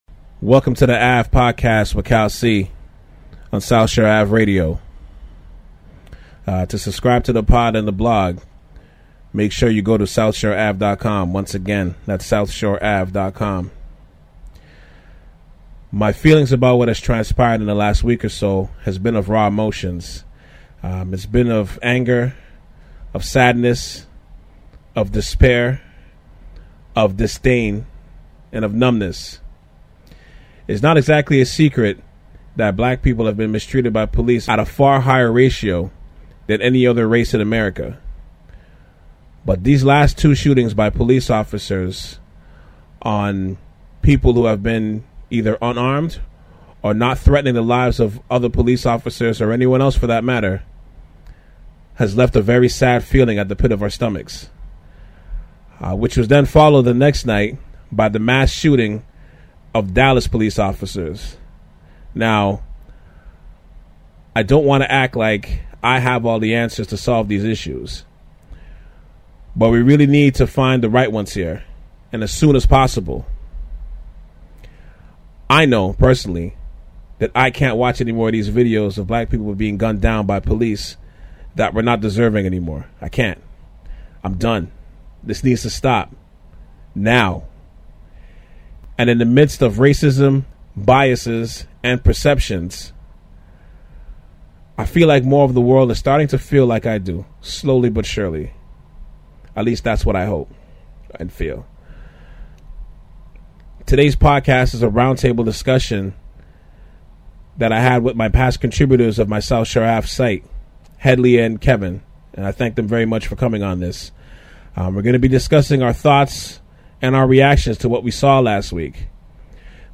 Roundtable Podcast discussion